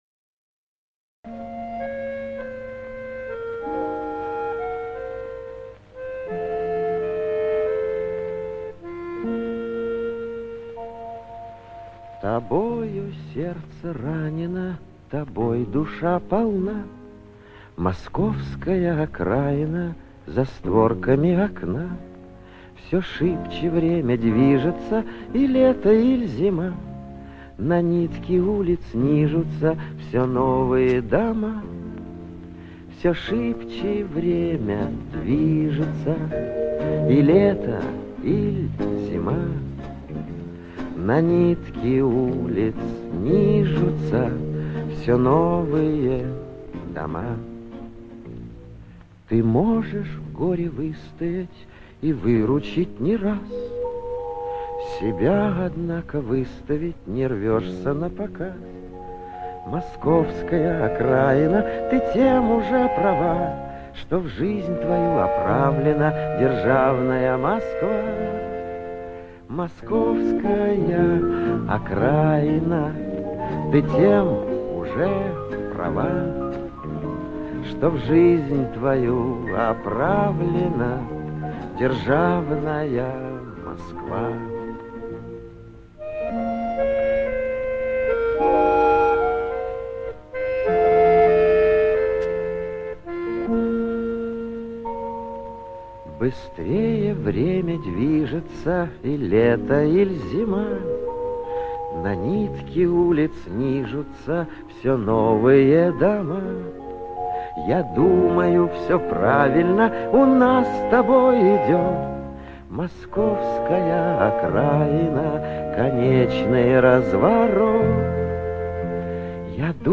Поёт, явно не профессионал, гадать не буду, бесполезно.